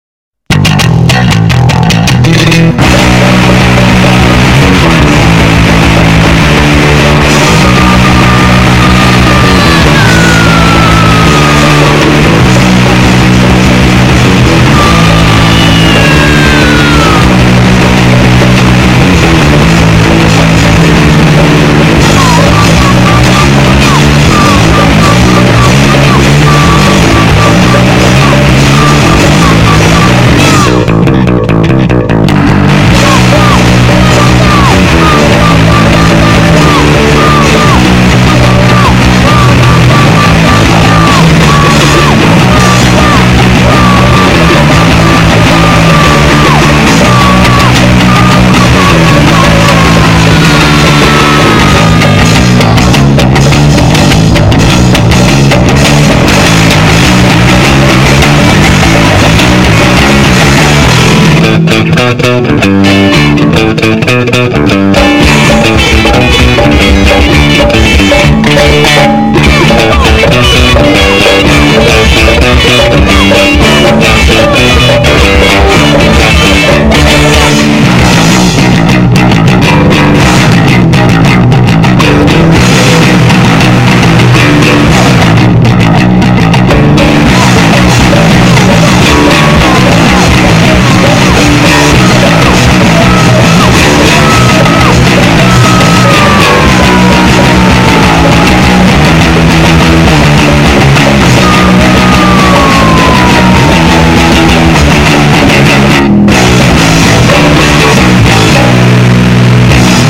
ショートチューンファストコア集。